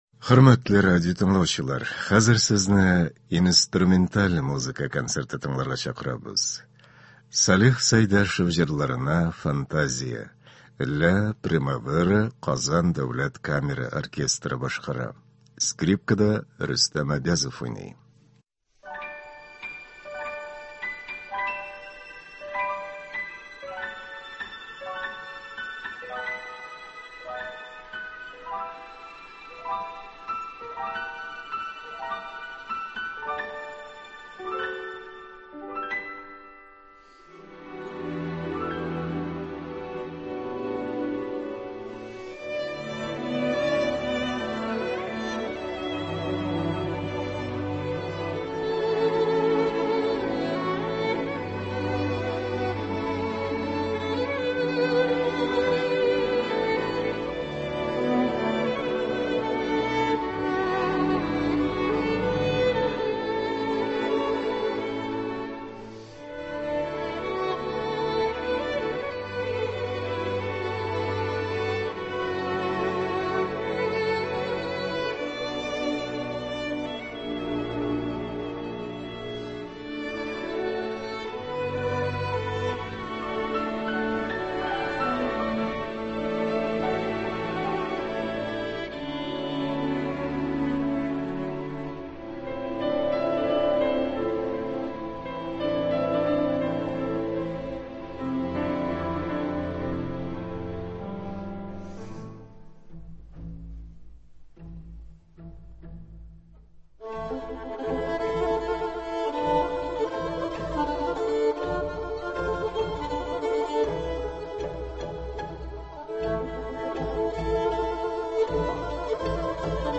Инструменталь музыка.